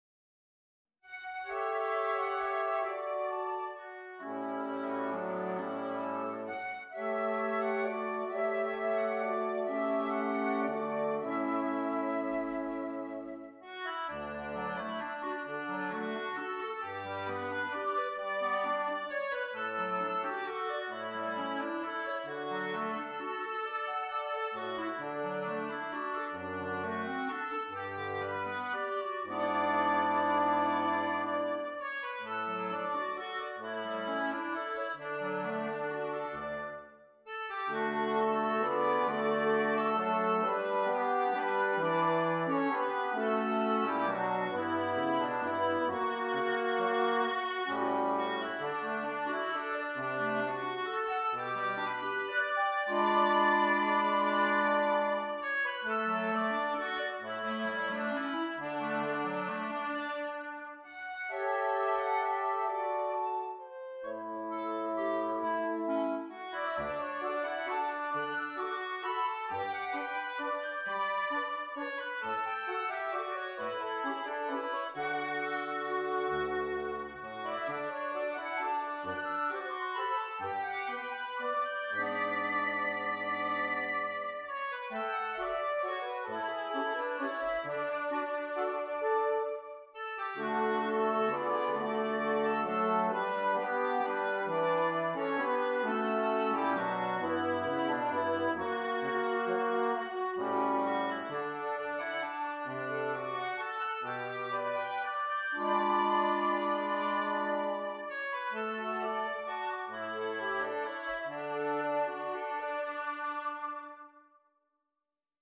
Woodwind Quintet
Traditional English Folk Song